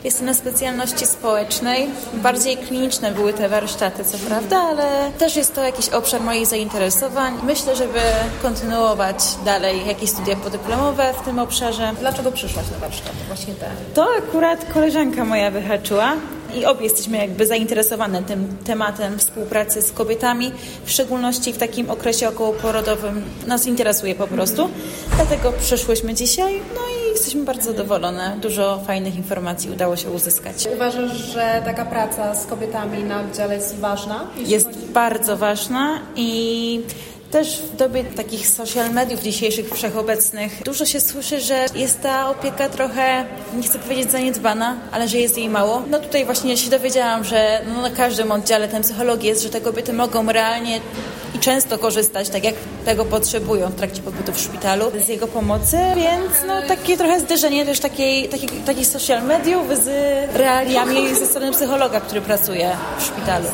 studentka 3. roku psychologii